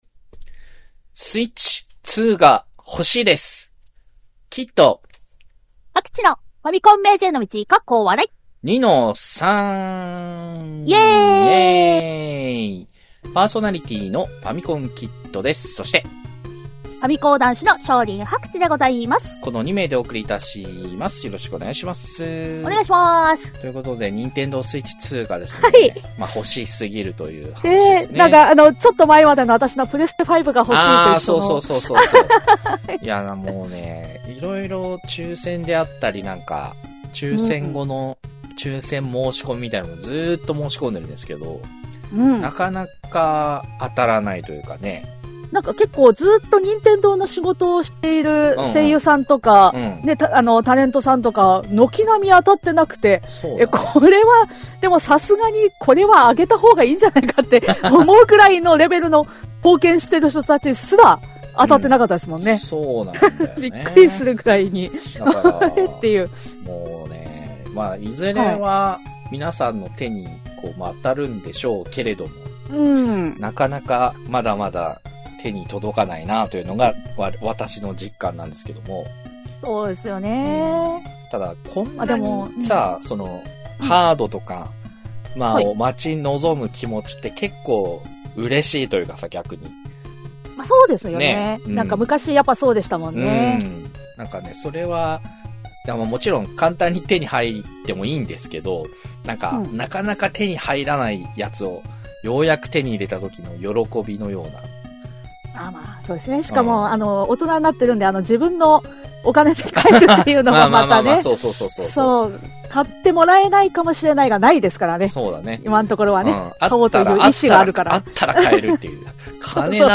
昭和っぽいテイストのインターネットラジオ、第20期2-3です！